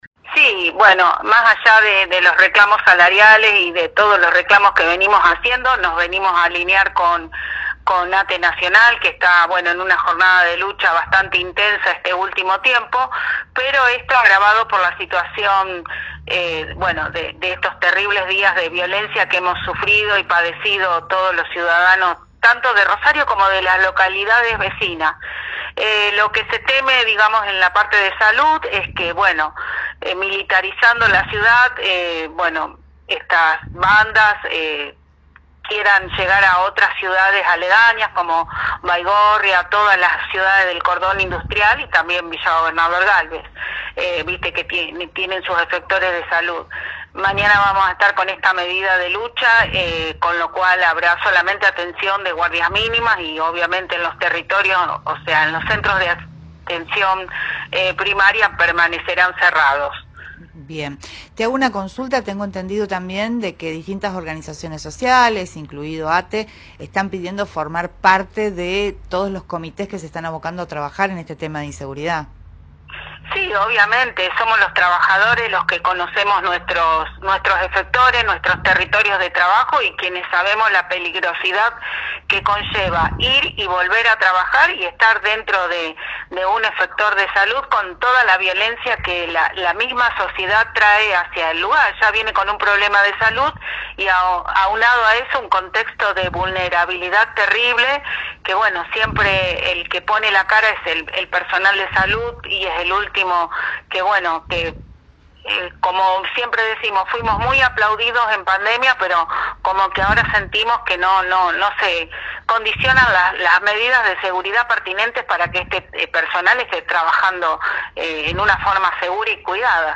en Primera Plana, por Cadena 3 Rosario.